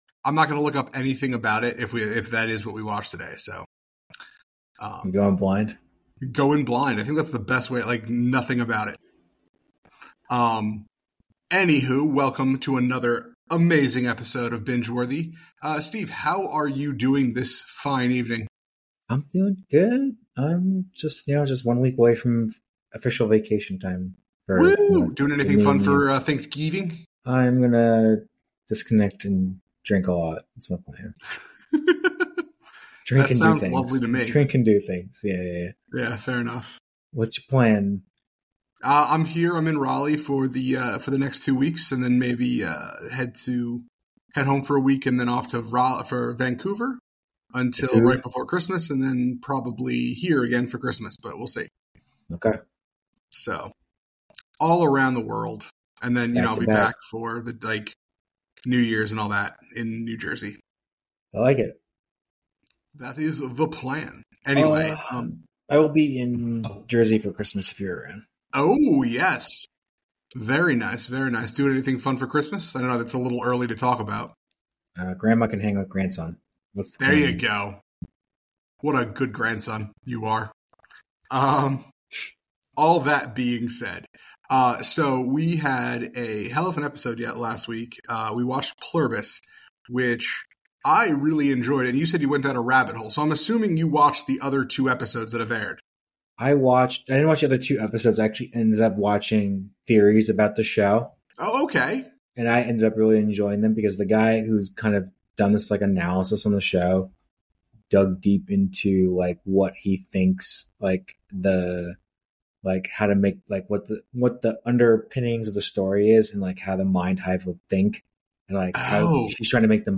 In this engaging conversation, the hosts delve into various themes surrounding the shows they are watching, particularly focusing on the pacing and